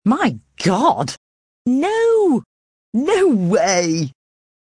TTS Audio Clip.mp3 📥 (29.33 KB)